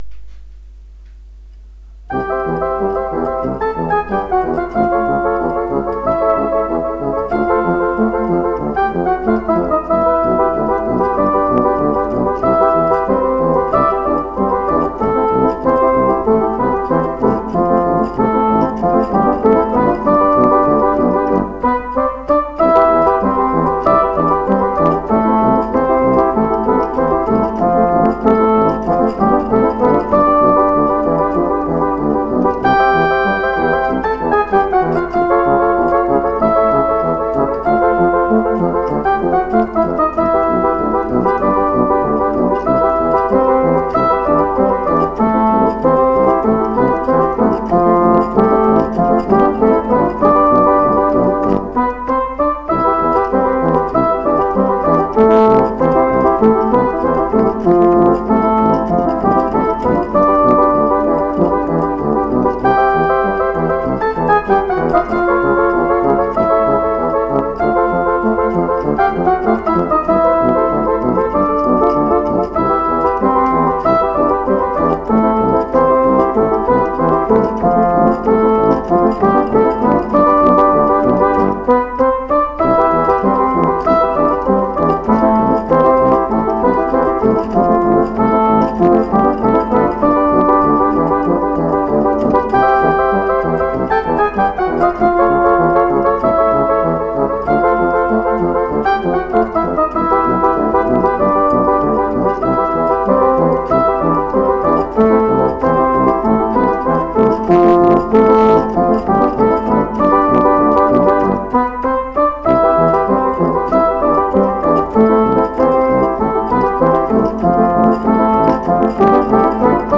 А на сайт пришлось занести мелодии в исполнении, со словами.